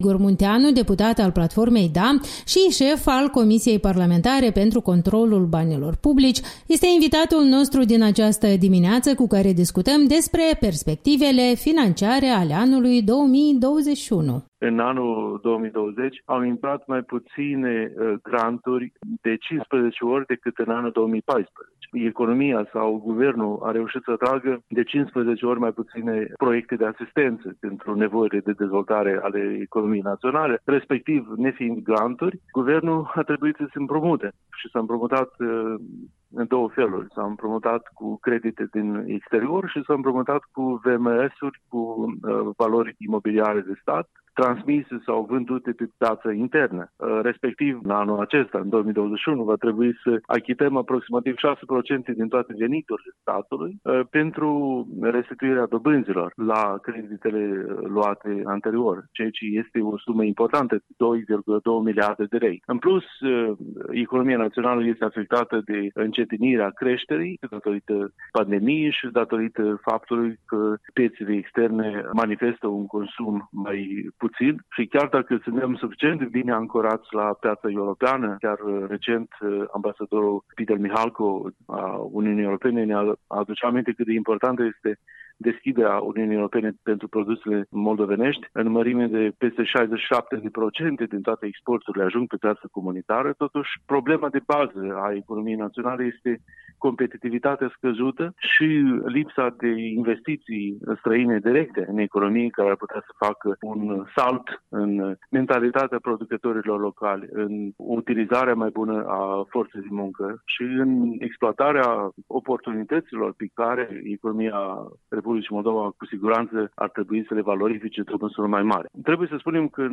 Interviul matinal cu deputatul DA, Igor Munteanu